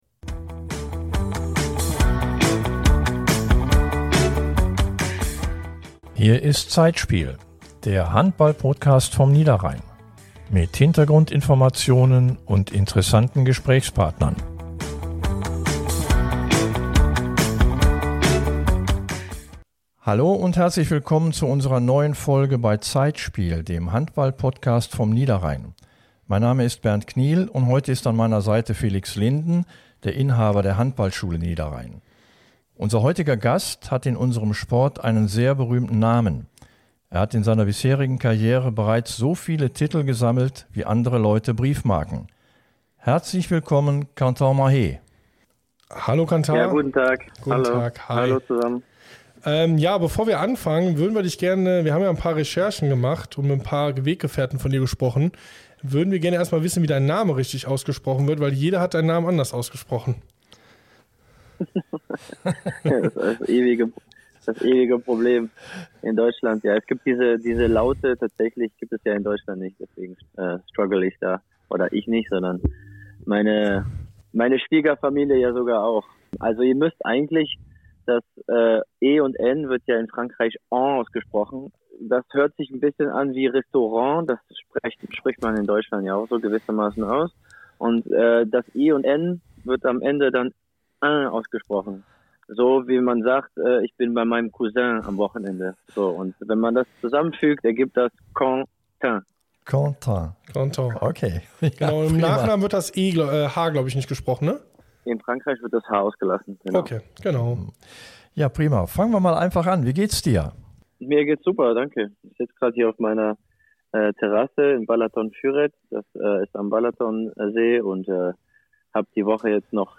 In unserer neuen Folge sprechen wir mit Kentin Mahé. Der französische Nationalspieler erzählt u.a. von seinem handballerischen Werdegang, seinen ersten Auftritten in der HBL und seinen vielen errungenen Titeln.
Viele Einspieler von verschiedenen Weggefährten und lustige Anekdoten runden ein hochinteressantes Gespräch ab.